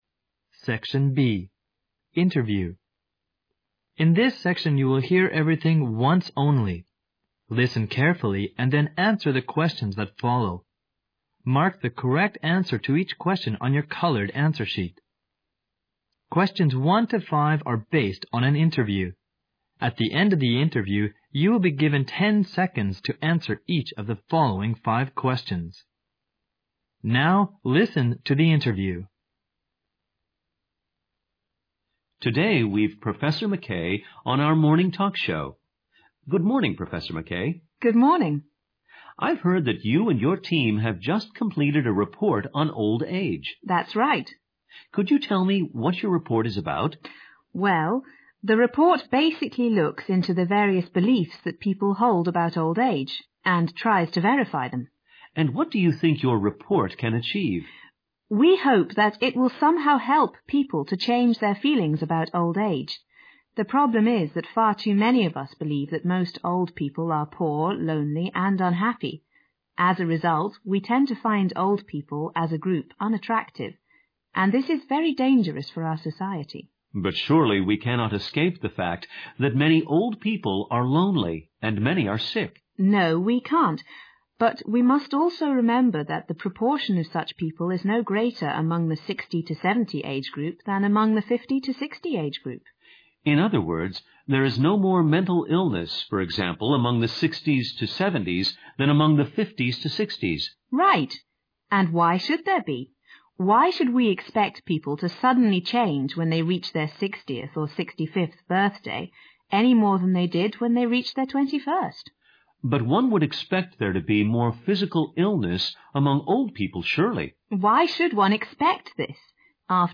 SECTION B INTERVIEW